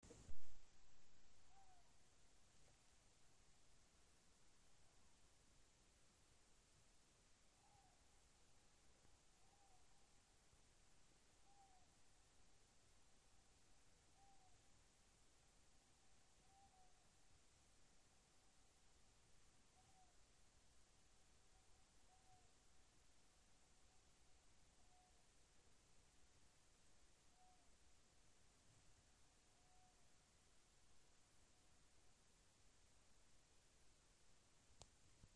Ausainā pūce, Asio otus
StatussDzied ligzdošanai piemērotā biotopā (D)
Piezīmes/atsaucas uz provocēšanu ;
manuprāt, netipiska balss (vēlāk pielikšu ierakstu)